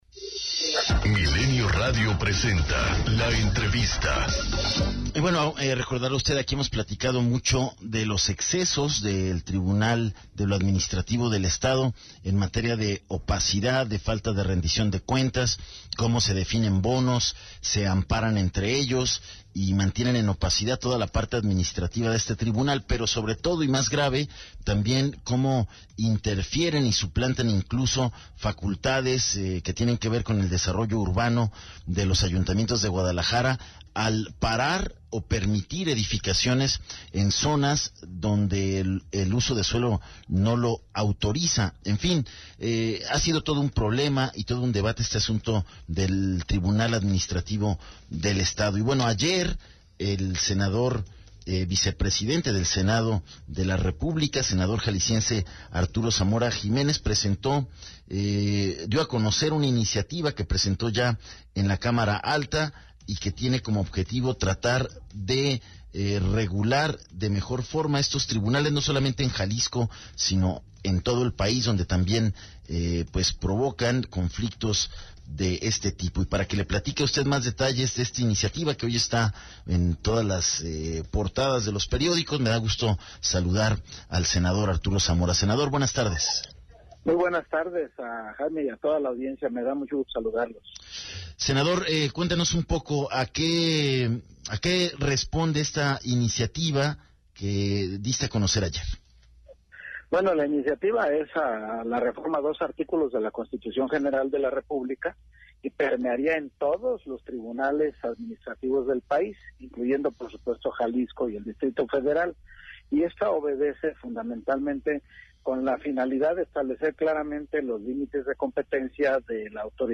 ENTREVISTA 210915